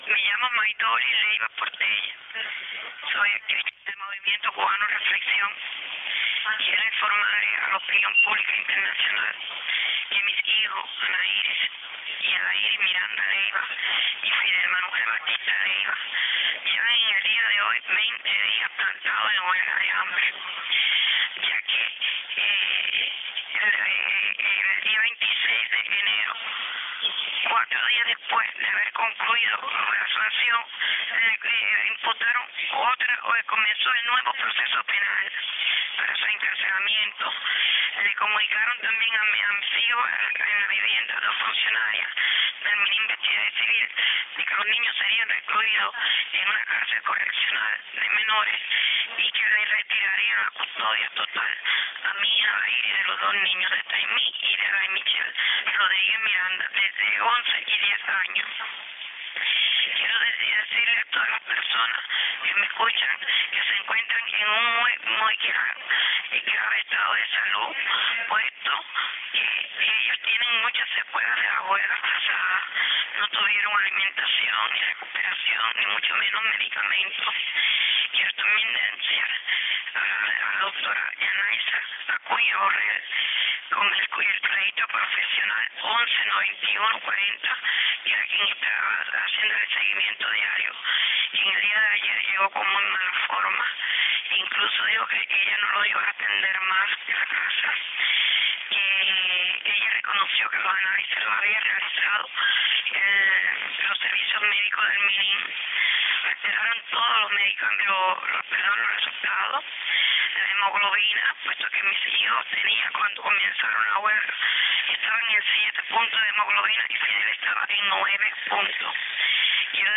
Entrevista Miércoles 14 de febrero